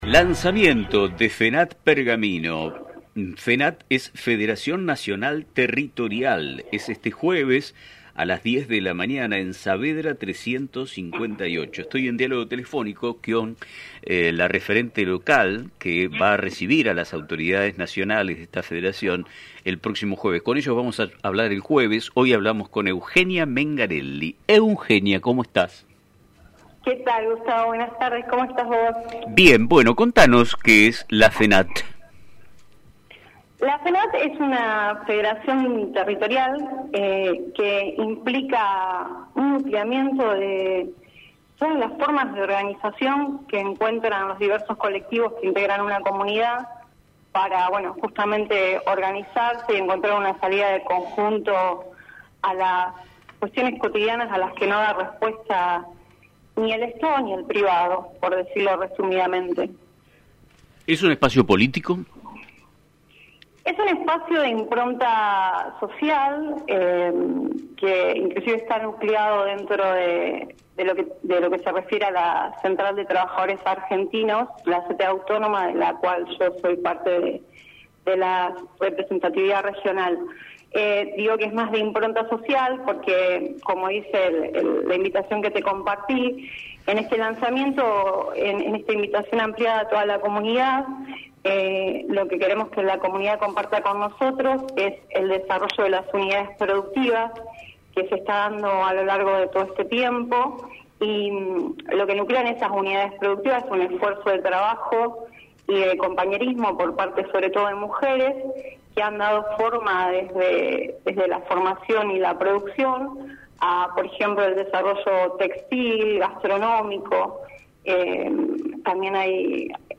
En La entrevista, detalló los preparativos para el evento que tendrá lugar este jueves a las 10 de la mañana en Saavedra 358.